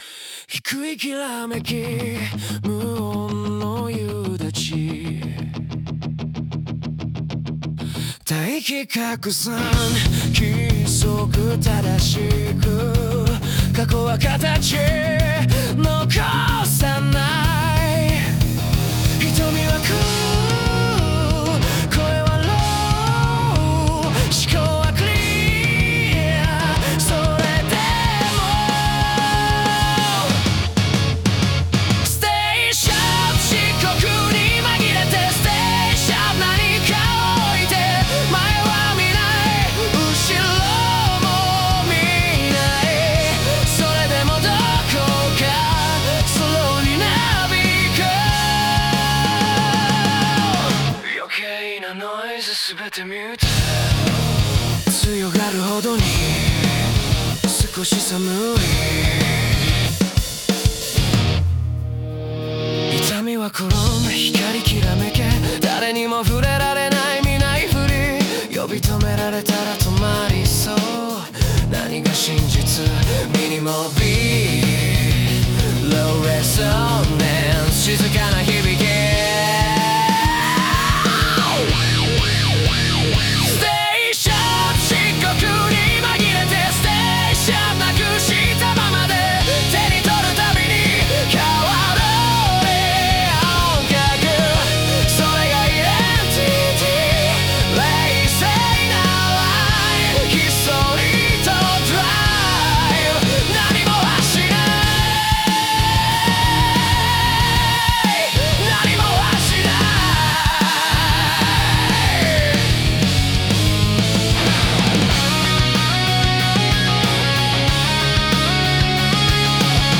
イメージ：邦ロック,男性ボーカル,激しい,重厚,180BPM,J-ROCKアンセム